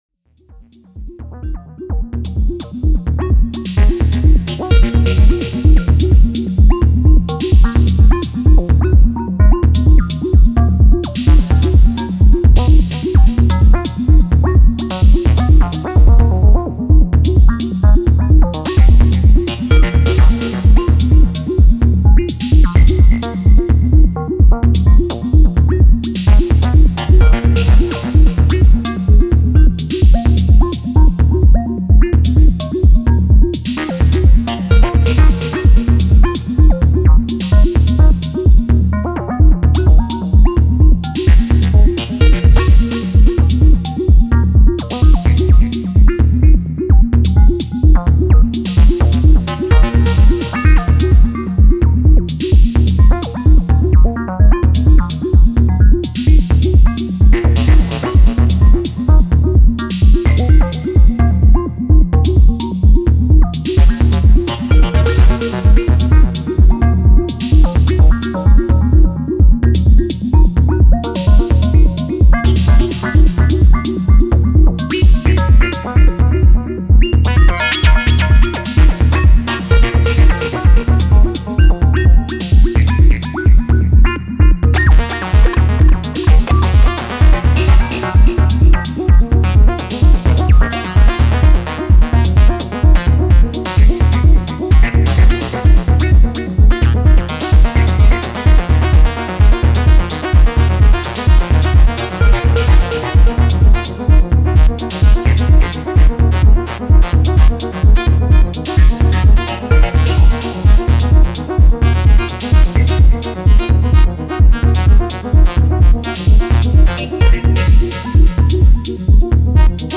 Hooked up digitone to chain (digitakt+big sky+analog heat) and have some fun playing with your patches
Very cool my friend, I really like your style how you arrange those patches, dark and groovy :dark_sunglasses: